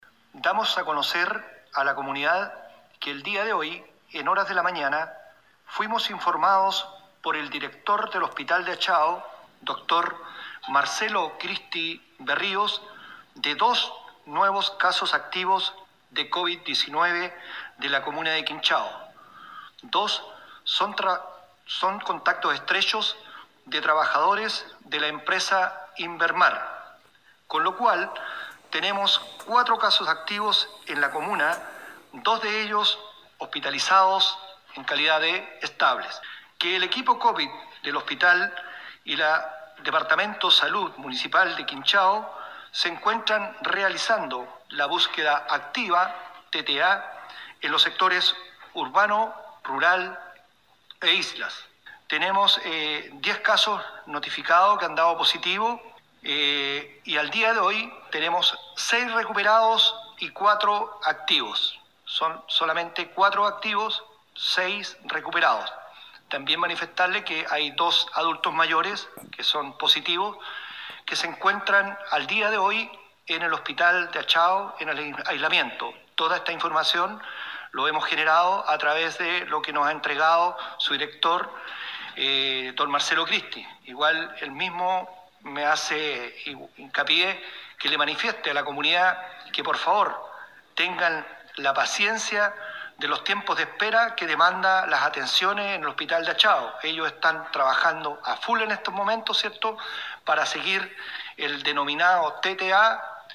Así lo dijo el alcalde Washington Ulloa por intermedio de las redes sociales del municipio, indicando que ambos corresponden a trabajadores de la empresa INVERMAR.